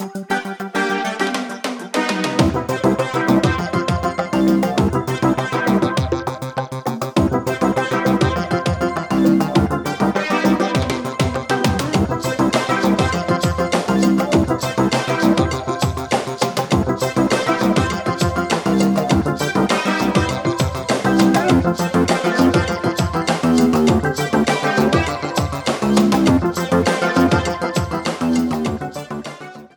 Ripped from game files
Fair use music sample